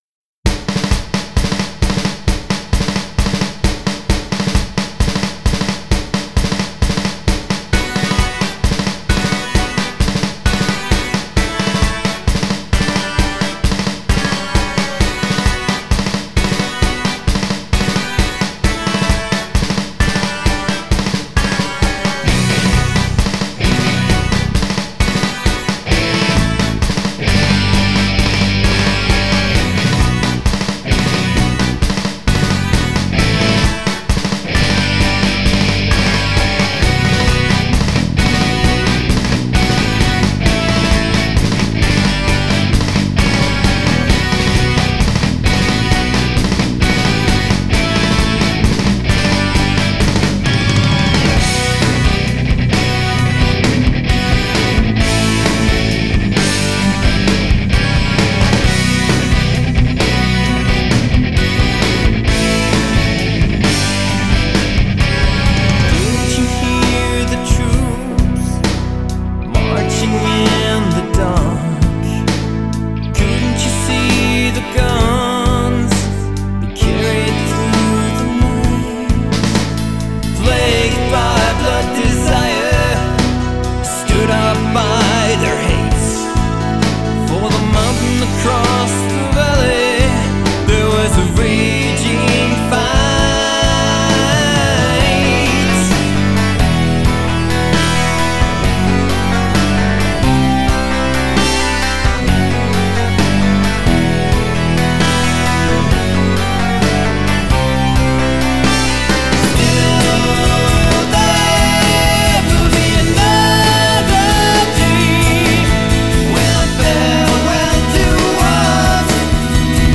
Rock
This is an anti-war song I wrote way back when there was still war in Bosnia. I finally found the time to record it decently.
It has me on vocals…and I’m not really a singer 😉 As usual I composed, arranged & recorded everything of this song, guitars, bass, keys, drums…and this time even vocals. The drums and bass takes were done about 2 years ago, in 2000.